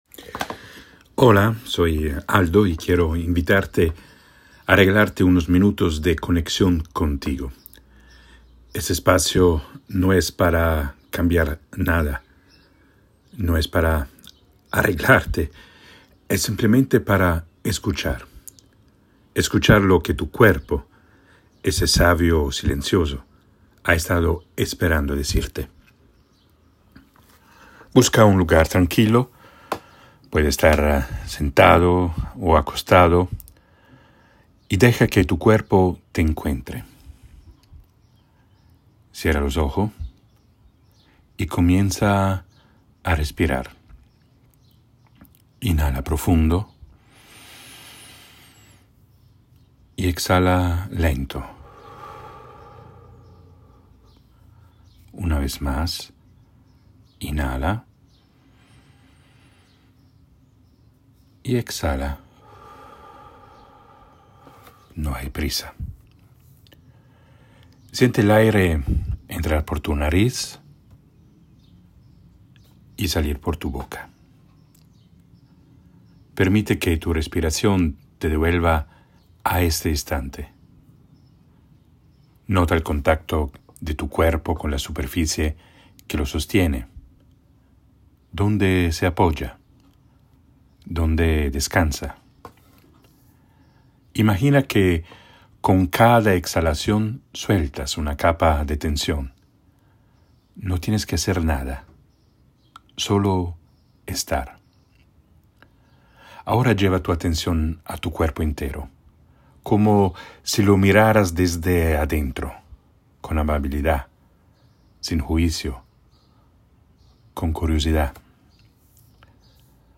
DESCARGA AQUÍ GRATIS MEDITACIÓN GUIADA: LO QUE EL CUERPO QUIERE DECIRME. Un audio guiado para escuchar lo que tu alma ha estado susurrando a través del cuerpo.